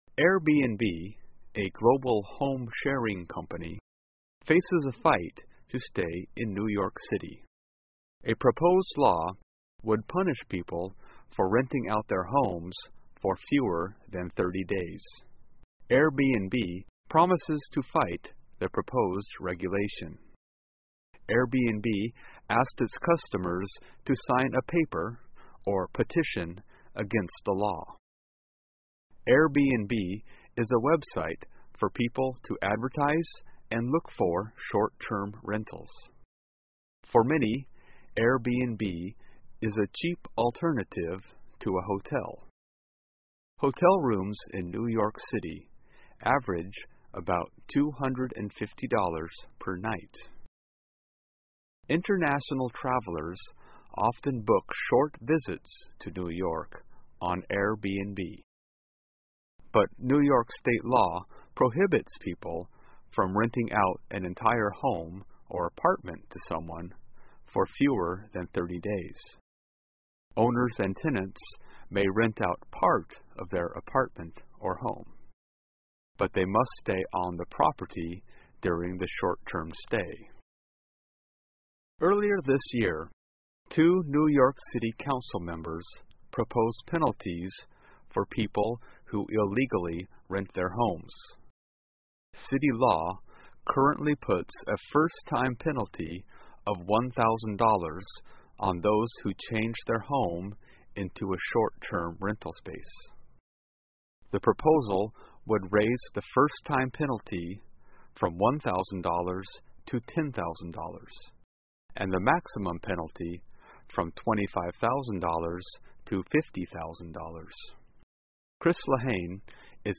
在线英语听力室因其非法房屋出租纽约对Airbnb进行处罚的听力文件下载,2015年慢速英语(十一)月-在线英语听力室